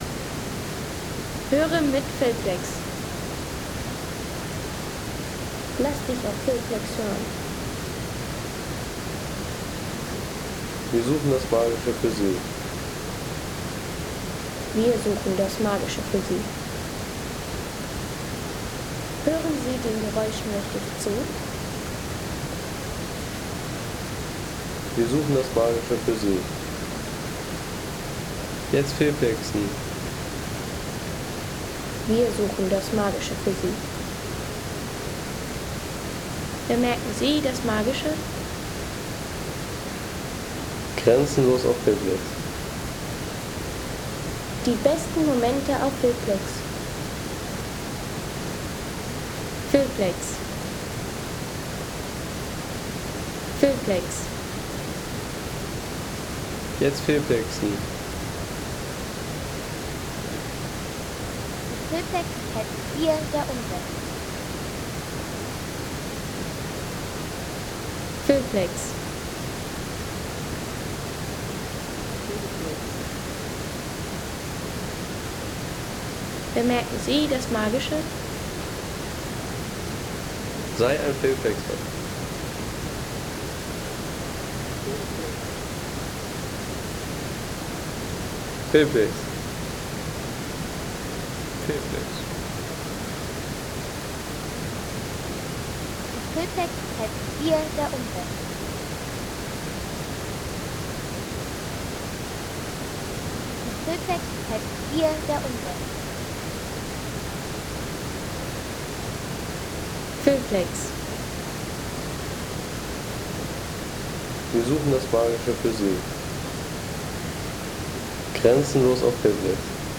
Landschaft - Bäche/Seen
Magische Klänge des Slapovi Milke Trnine im Nationalpark Plitvicer Se ... 3,50 € Inkl. 19% MwSt.